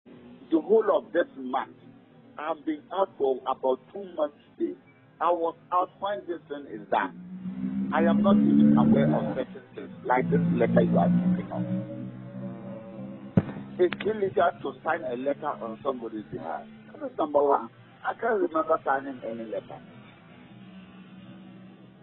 In an interview with Upperwestmedia Team, he explained that he was away one some business trips down south of the country and in the period he believes certain developments took place at his blind side.